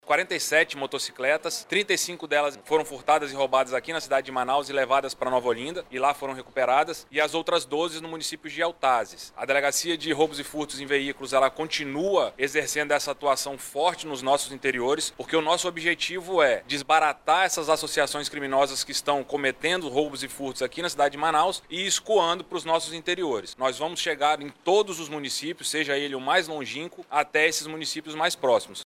O delegado-geral da Polícia Civil do Amazonas, Bruno Fraga, destaca que outras operações vão ser deflagradas em todos os municípios do Estado para desarticular quadrilhas especializadas em roubos e furtos de veículos.